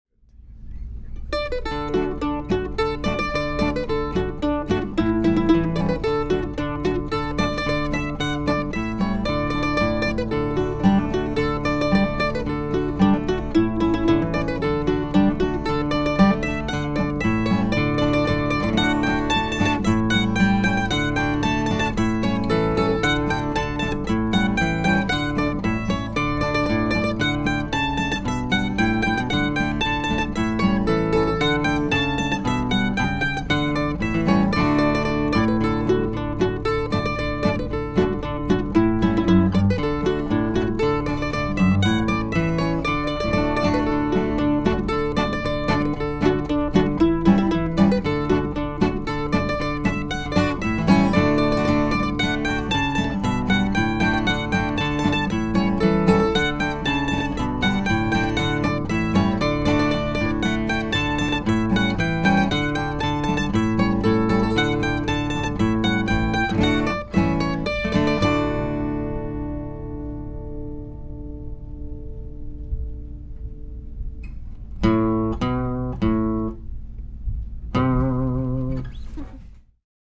were recorded in the recording class
This is an old time Appalachian traditinoal tune
Mandolin
Guitar